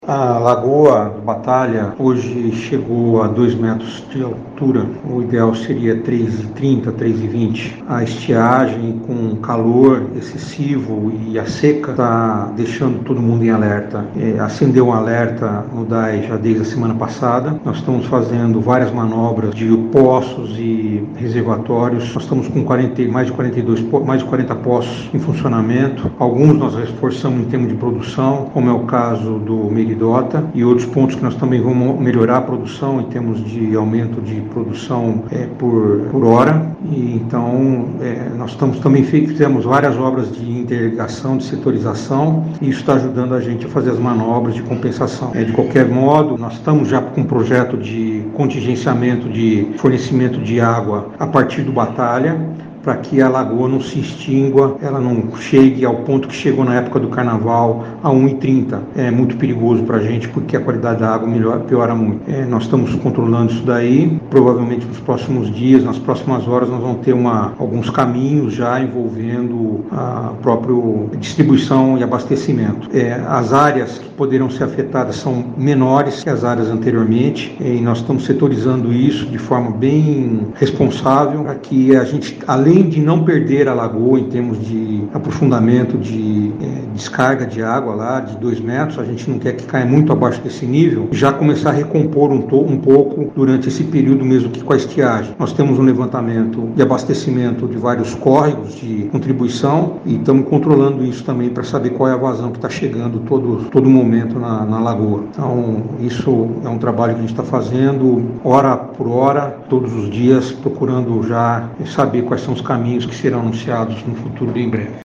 Leandro Joaquim, presidente do Departamento de Água e Esgoto, alertou sobre a situação atual e quais são as estratégias para este mês de estiagem.